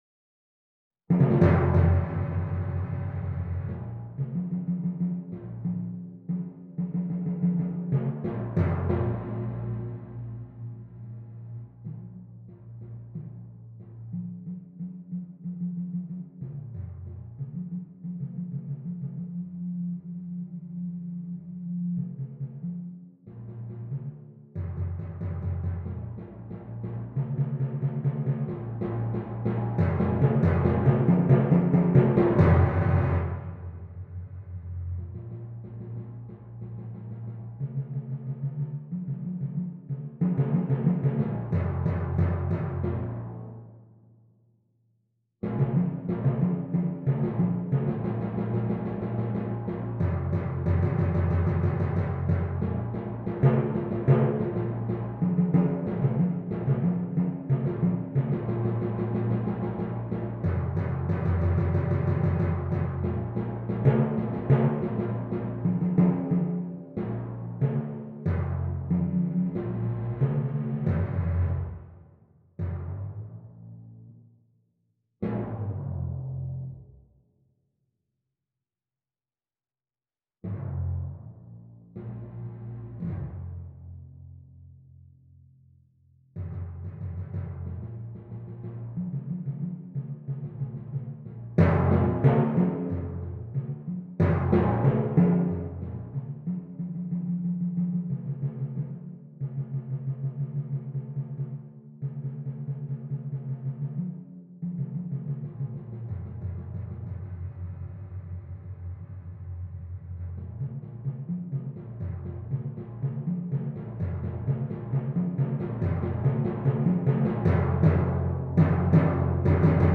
Solo Timpani